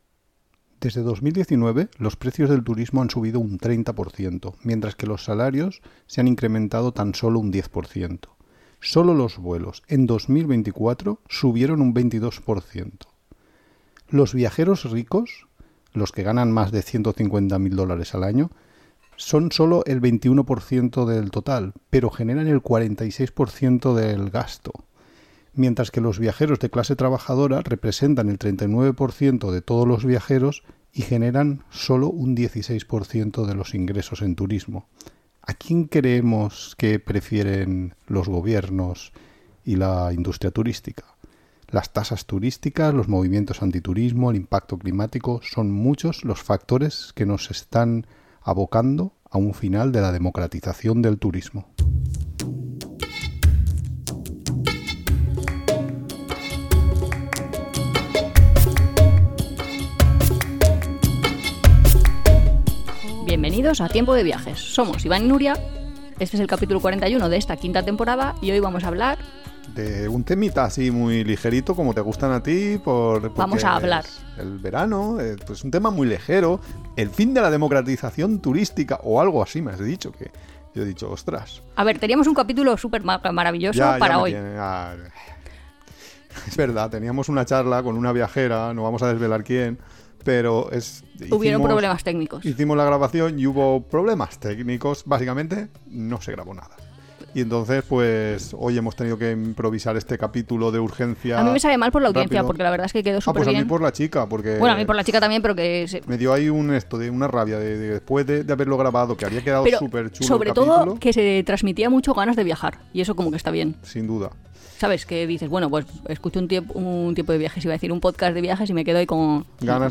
Hoy tenemos un episodio un poco diferente, ya que, por unos problemas técnicos inesperados, tuvimos que improvisar sobre la marcha.